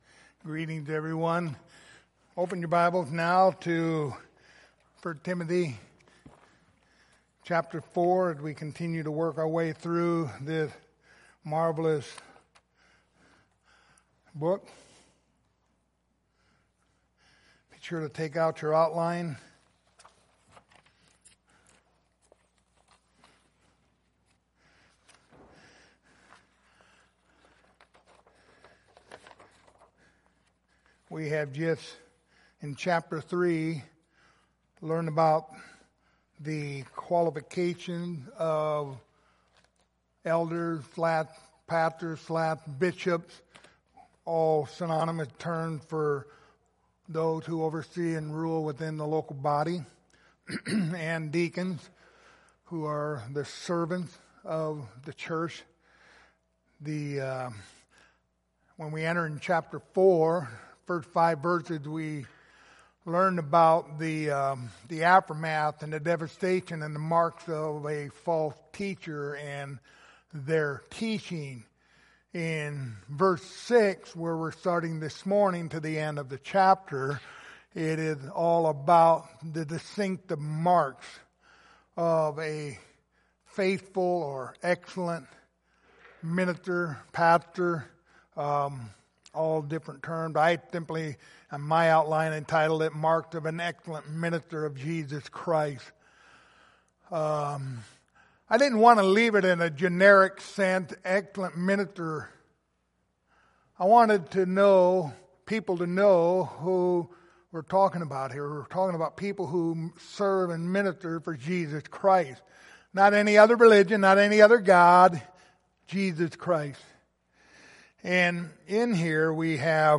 Passage: 1 Timothy 4:6-11 Service Type: Sunday Morning